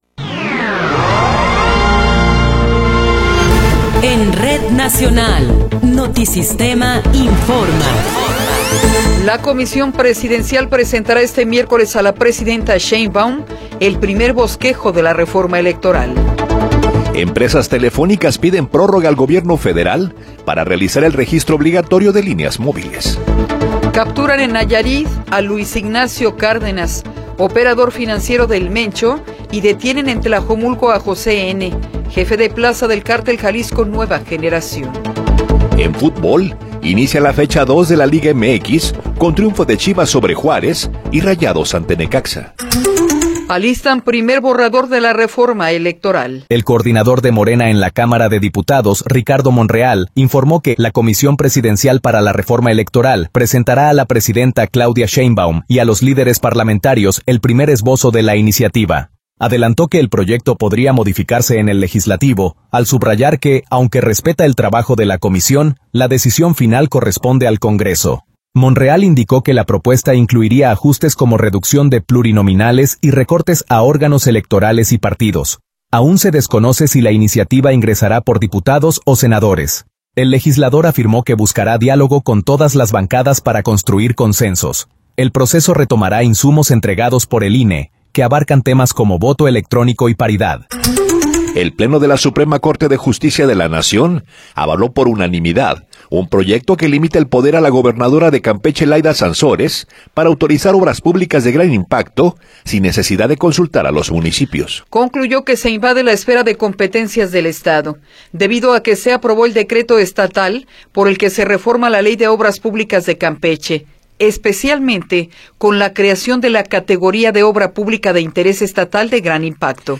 Noticiero 8 hrs. – 14 de Enero de 2026